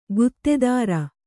♪ guttedāra